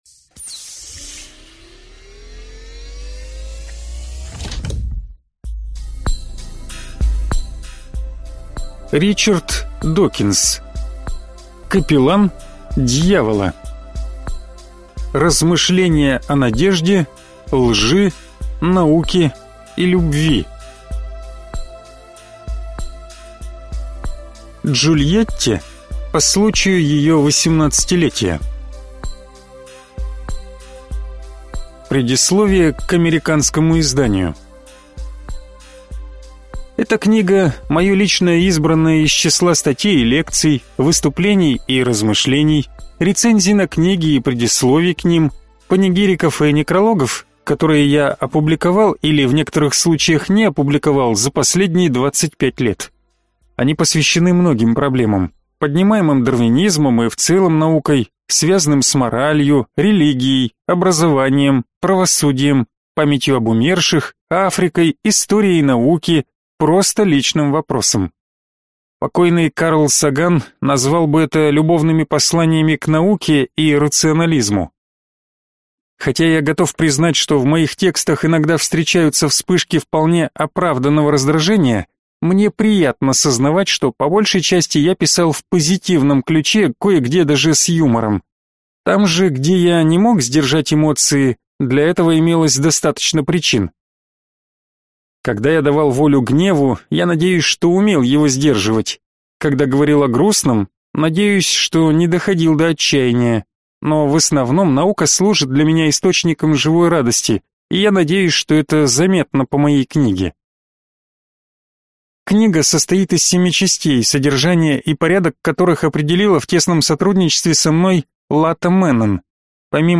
Аудиокнига Капеллан дьявола. Размышления о надежде, лжи, науке и любви | Библиотека аудиокниг